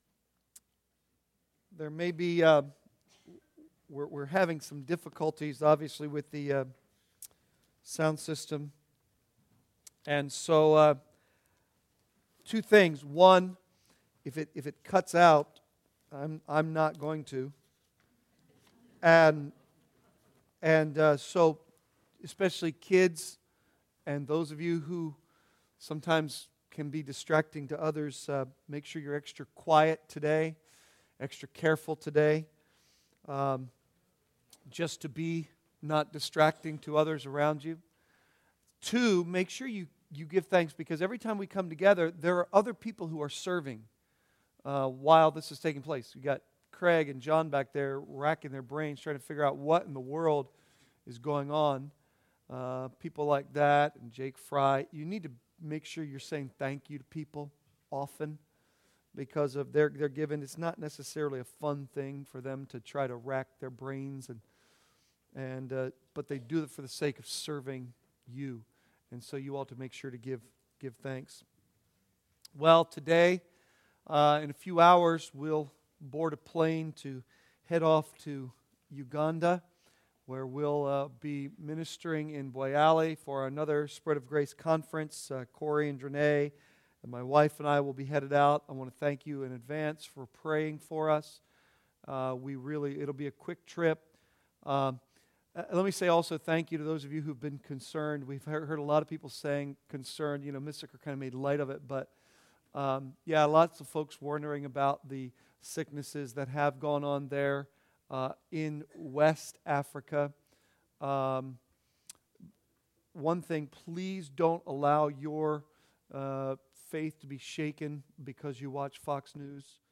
Sermons Archive - Page 66 of 95 - Calvary Bible Church - Wrightsville, PA